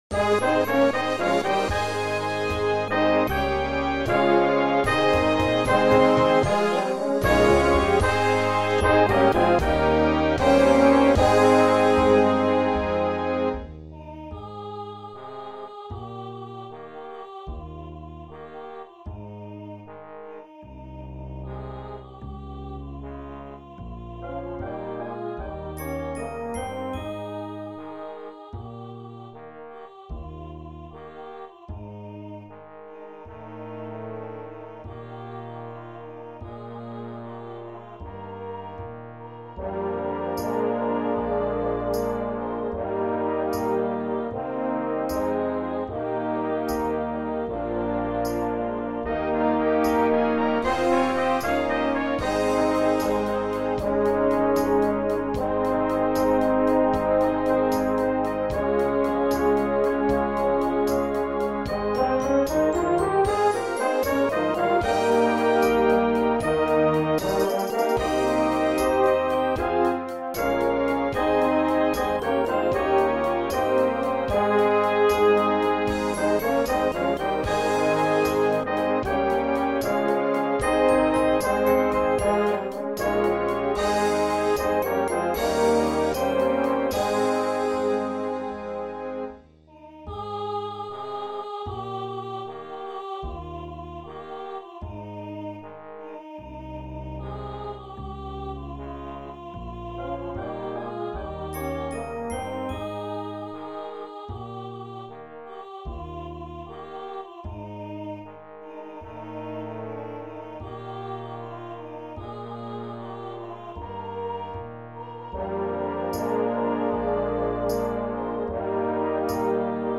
66B1 Brass Band $25.00
(computer generated sound sample)
For band and soloist - vocal or any instrument